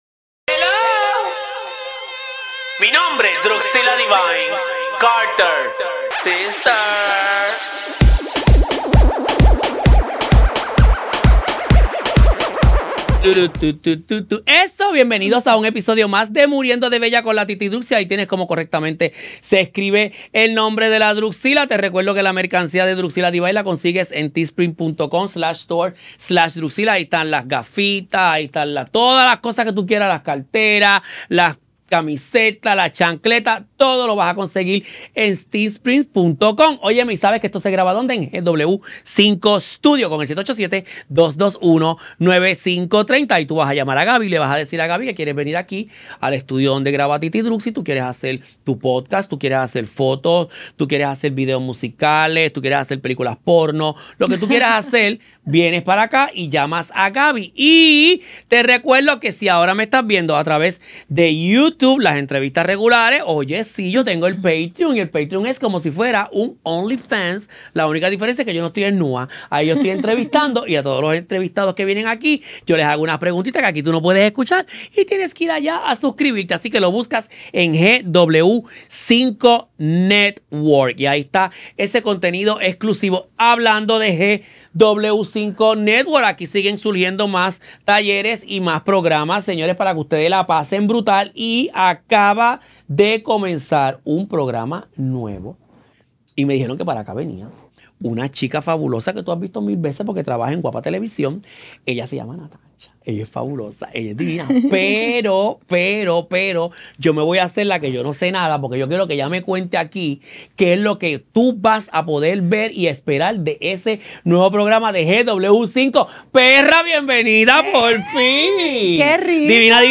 Daniela Droz habla de su Verdadera Entrega a Dios ... y me invita hacer lo mismo. Grabado en los estudios de GW-Cinco y somos parte del GW5 Network.